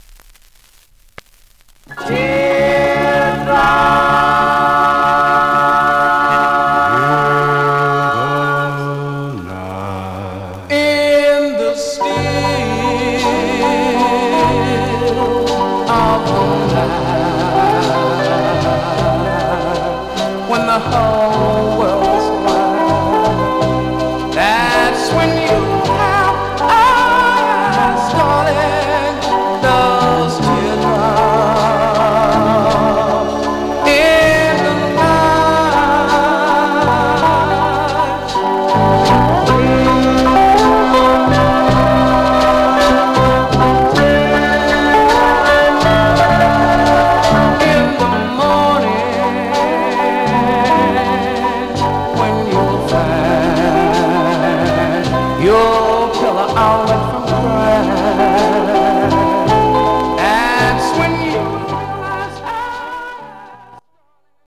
Condition Some surface noise/wear Stereo/mono Mono
Male Black Groups